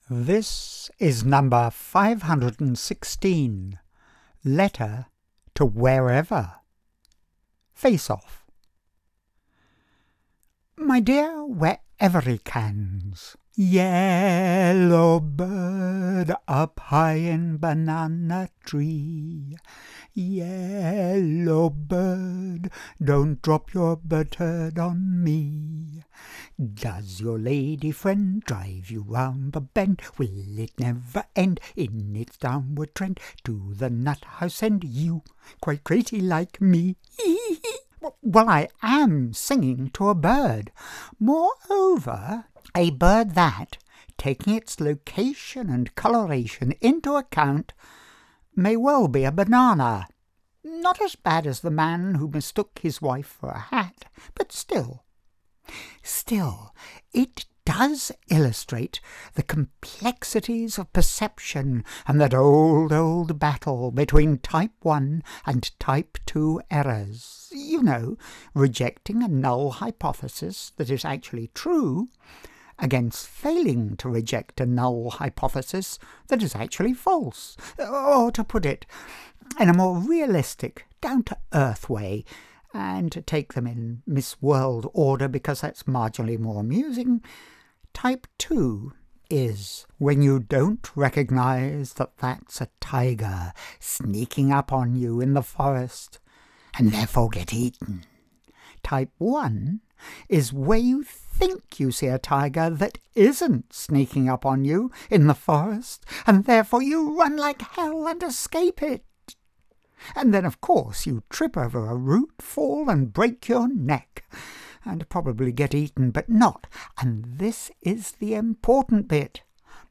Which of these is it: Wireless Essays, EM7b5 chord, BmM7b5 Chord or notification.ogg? Wireless Essays